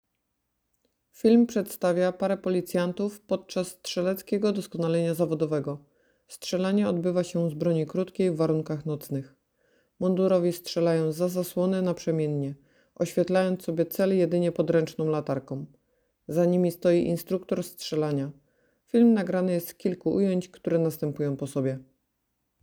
Tym razem trening był zorganizowany w warunkach, które symulowały noc i odbywał się z broni krótkiej.
Zorganizowane zajęcia odbyły się w obiekcie Komendy Miejskiej Policji w Bielsku-Białej i były skierowane do wszystkich mundurowych garnizonu pszczyńskiego.
Policjanci strzelali naprzemiennie, zza zasłony, oświetlając sobie cel jedynie podręczną latarką.